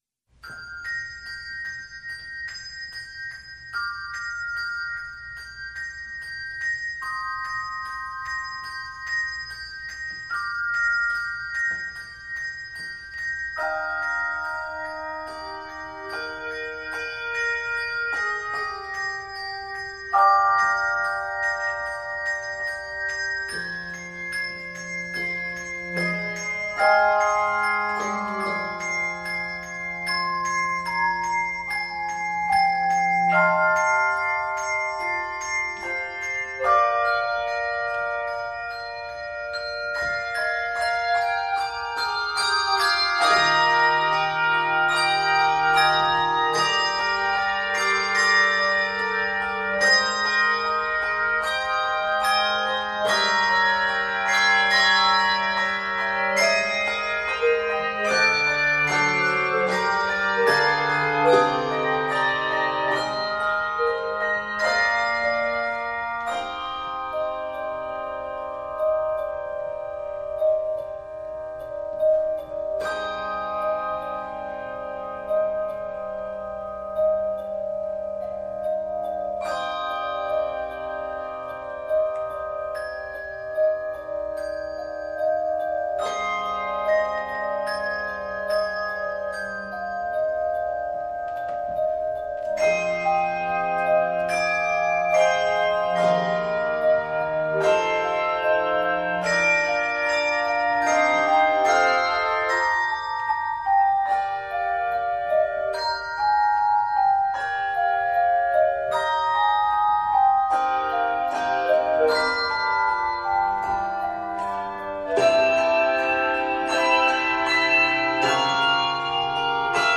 From the ethereal to the bold